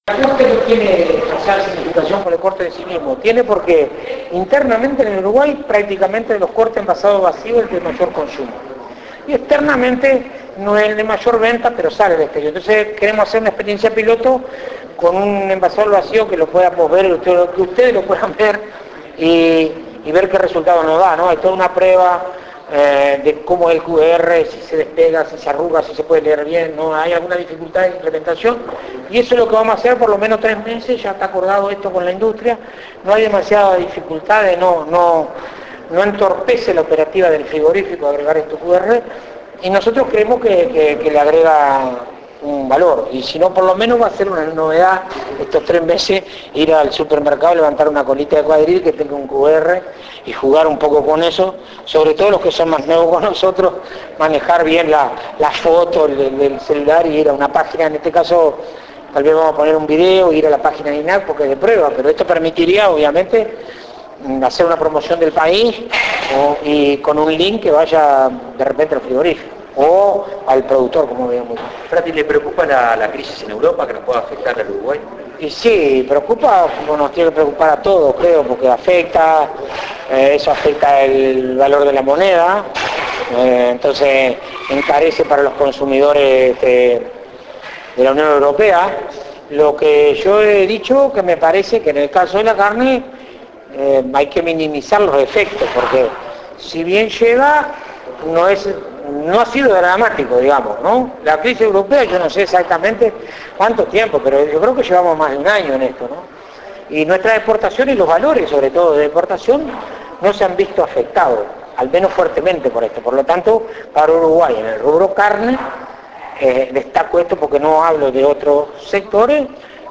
Presidente de INAC
fratti_entrevista.mp3